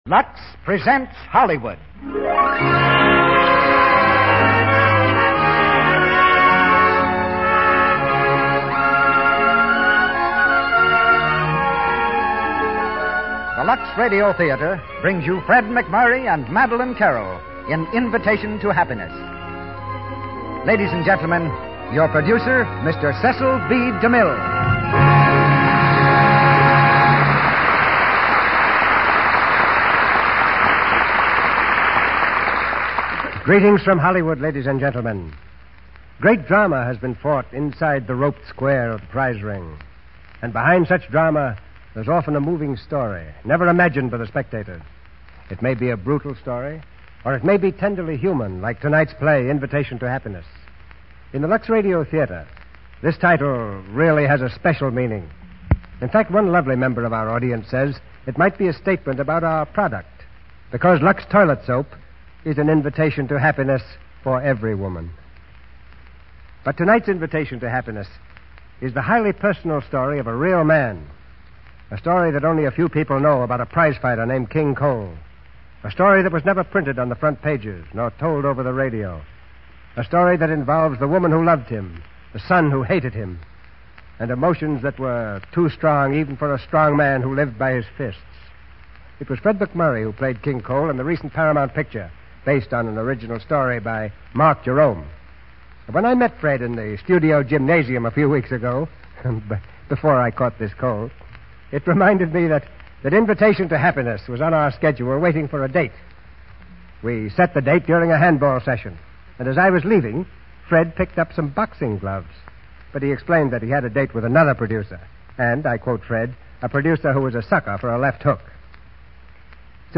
Invitation to Happiness, starring Fred MacMurray, Madeleine Carroll